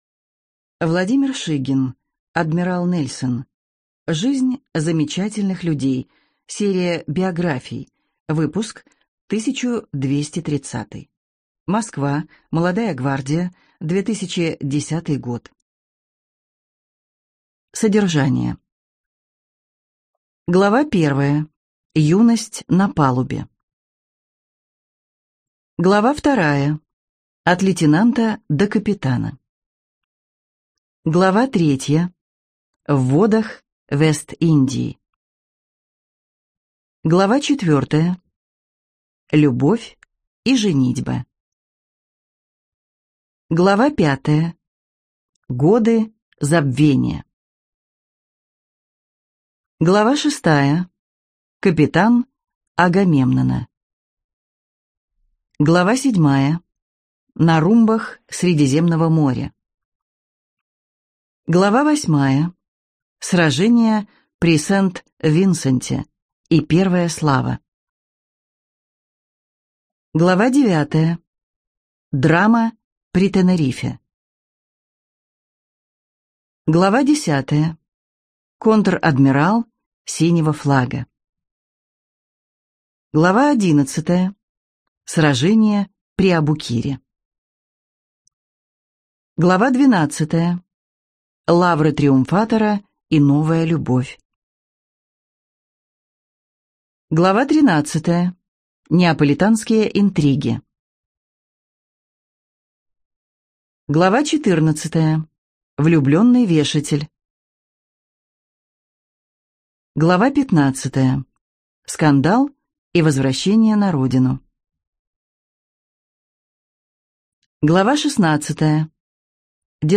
Аудиокнига Адмирал Нельсон | Библиотека аудиокниг